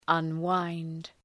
Shkrimi fonetik {ʌn’waınd}